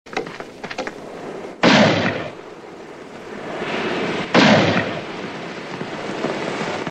• TWO GUNSHOTS.wav
TWO_GUNSHOTS_vzK.wav